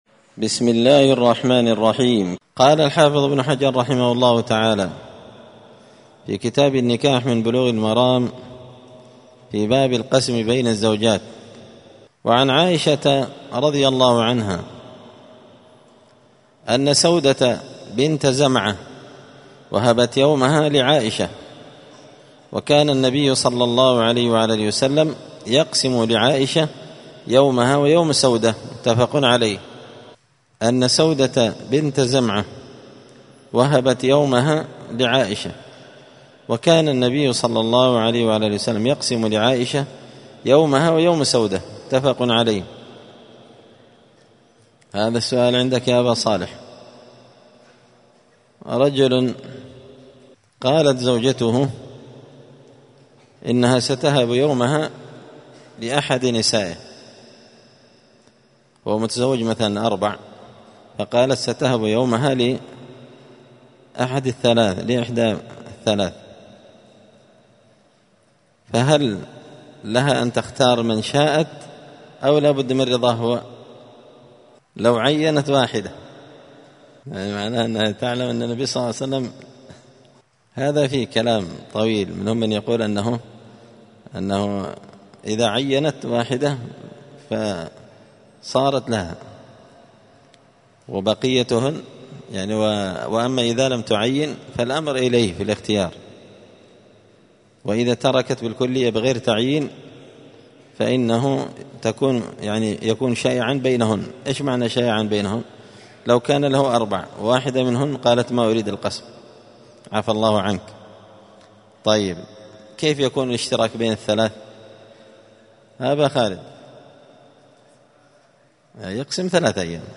مسجد الفرقان_قشن_المهرة_اليمن
*الدرس 36 {تابع لباب القسم بين النساء}*